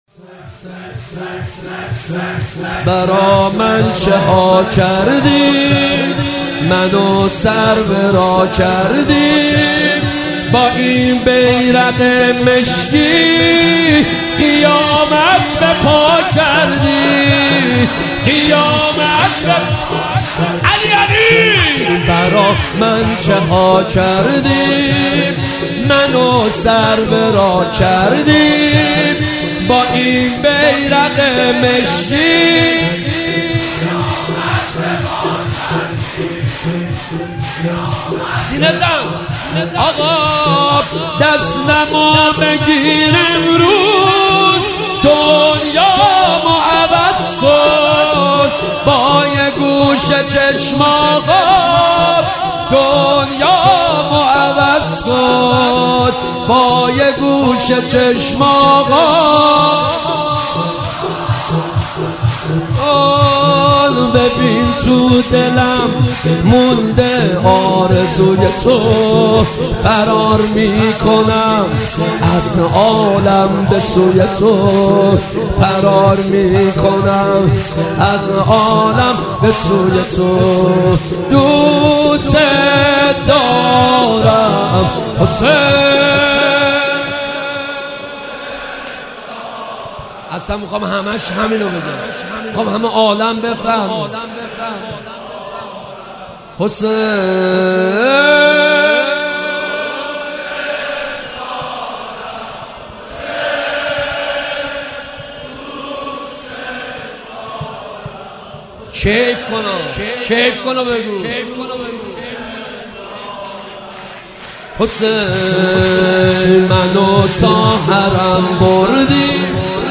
مداحی شب دوم محرم 1397